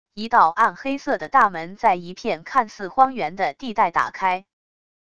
一道暗黑色的大门在一片看似荒原的地带打开wav音频